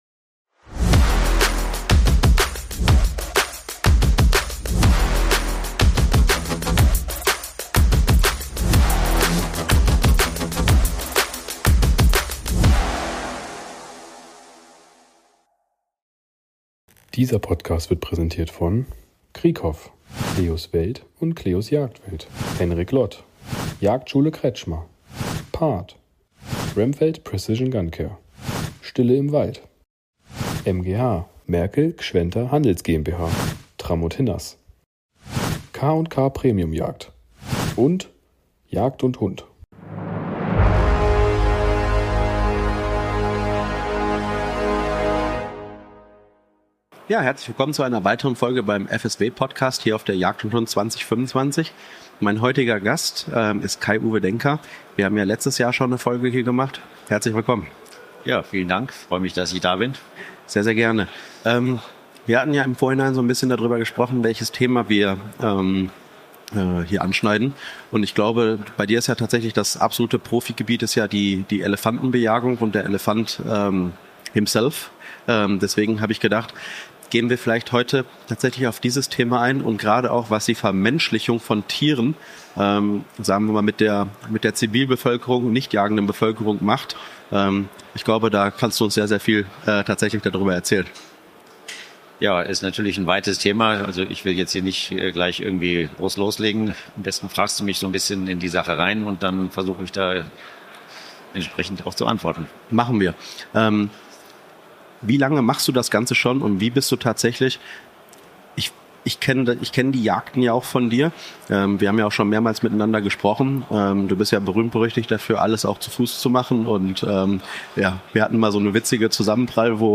Willkommen auf der Jagd & Hund 2025! Wir sind live auf Europas größter Jagdmesse unterwegs und sprechen mit spannenden Gästen aus der Jagdszene.